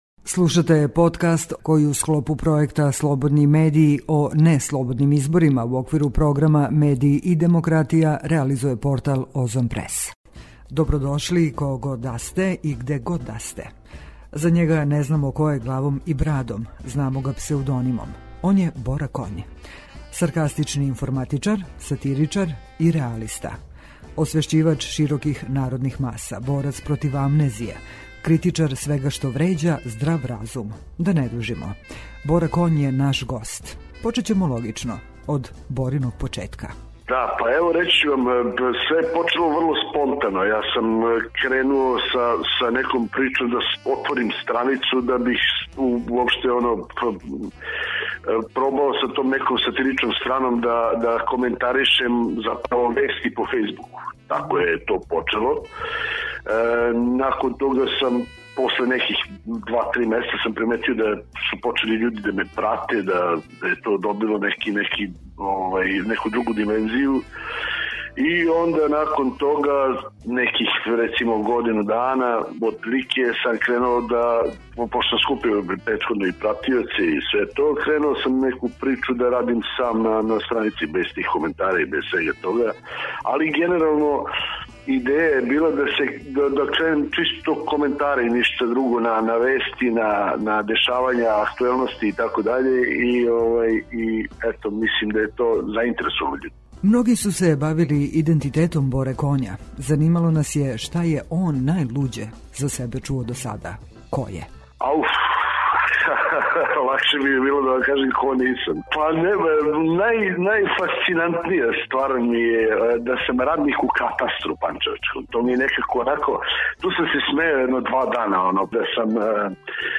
A zašto smo odlučili za ovaj razgovor sa njim.